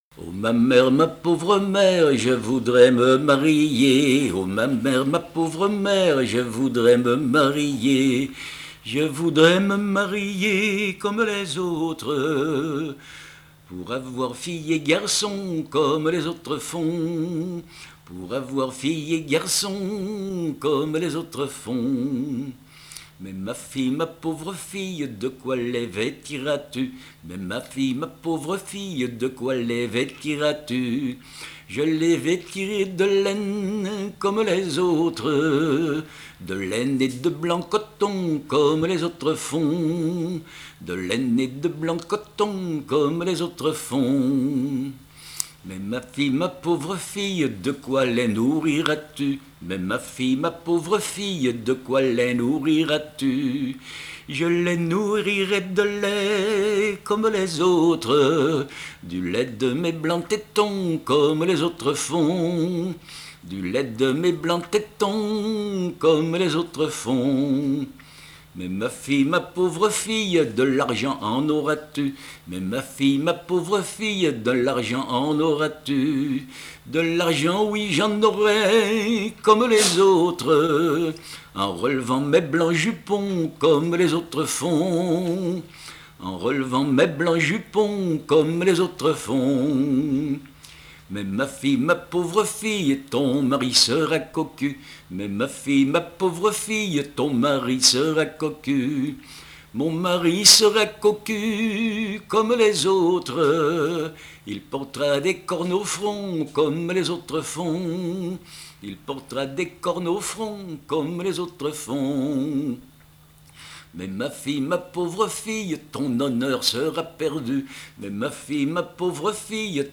Dialogue mère-fille
Pièce musicale inédite